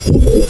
breath.wav